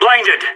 CSGO Blinded Sound Effect Free Download